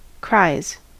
Ääntäminen
Ääntäminen US Tuntematon aksentti: IPA : /kɹaɪ̯z/ Haettu sana löytyi näillä lähdekielillä: englanti Käännöksiä ei löytynyt valitulle kohdekielelle.